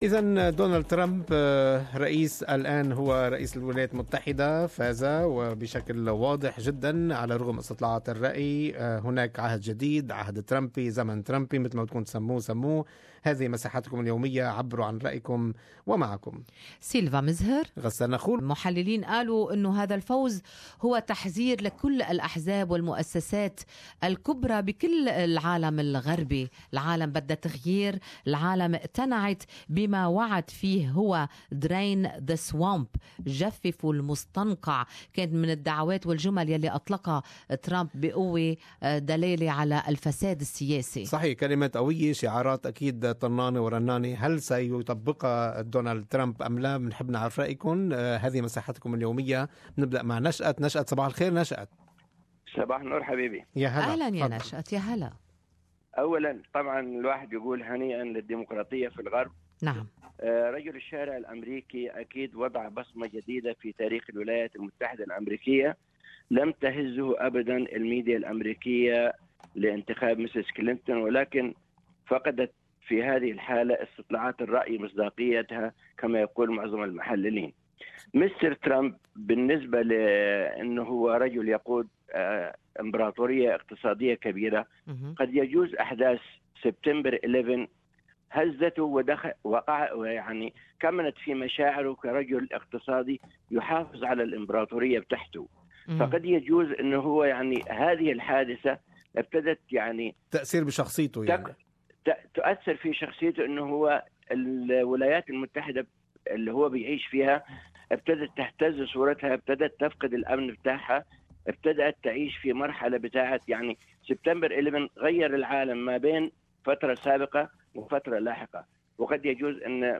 Talkback: Donald Trump 45th US President